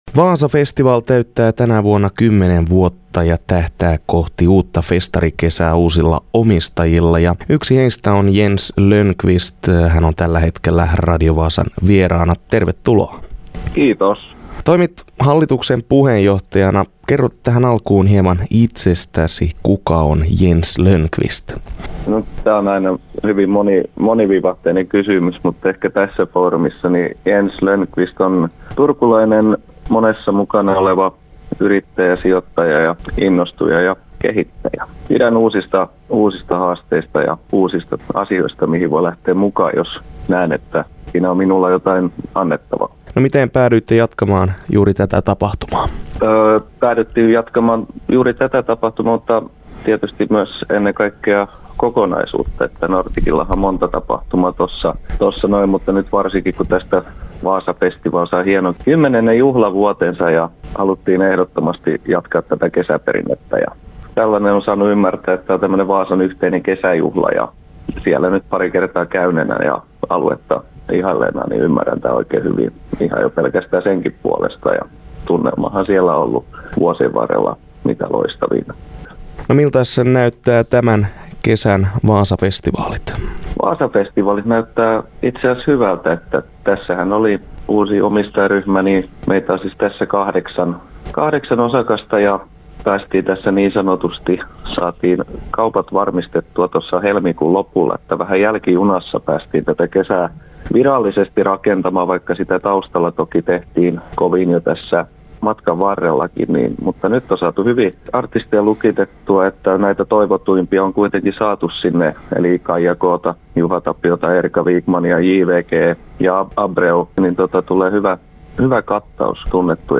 haastattelussa
on vieraana puhelimitse